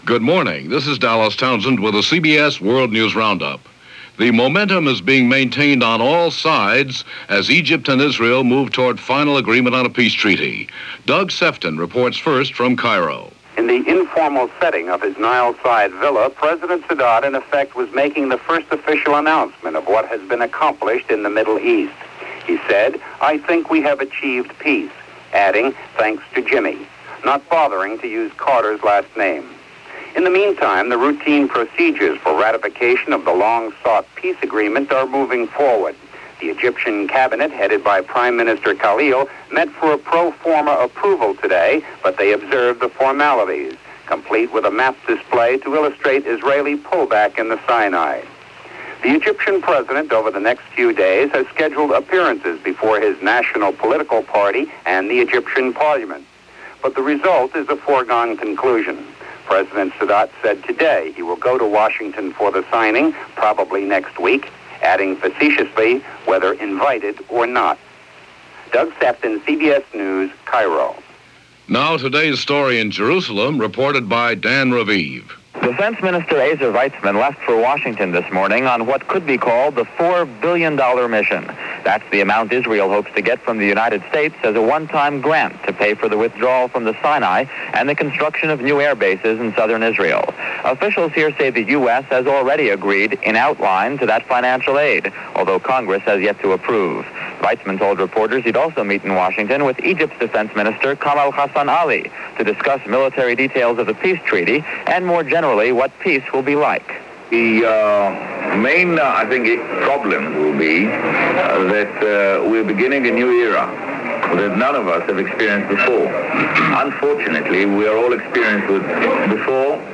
March 15, 1979 – CBS World News Roundup